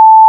Combat (8): sword, bow, zombie_hit, zombie_death, hurt, shield, explosion, raider
**⚠  NOTE:** Music/SFX are PLACEHOLDERS (simple tones)
bow_release.wav